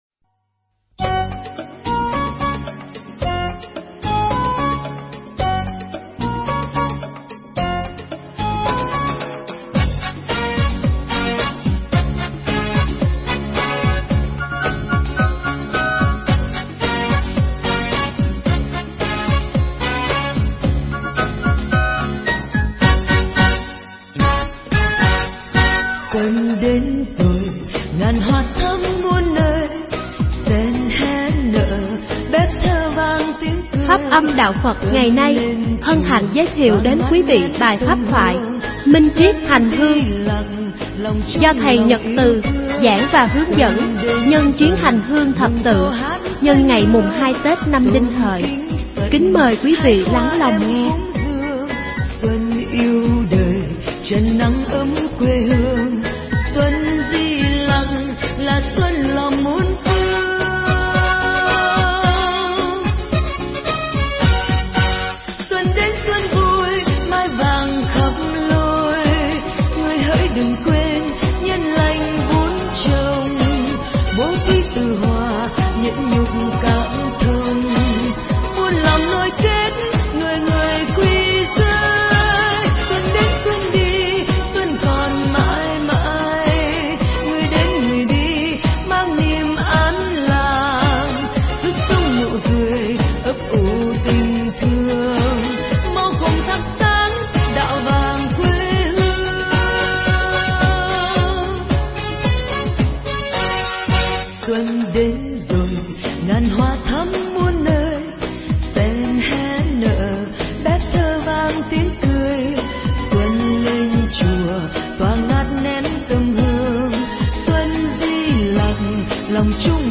Nghe mp3 thuyết pháp Minh Triết Hành Hương
giảng nhân chuyến hành hương thập tự ngày mùng 2 tết Đinh Hợi năm 2007.